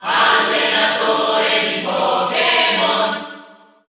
Crowd cheers (SSBU) You cannot overwrite this file.
Pokémon_Trainer_Male_Cheer_Italian_SSBU.ogg